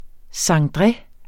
Udtale [ sɑŋˈdʁε ]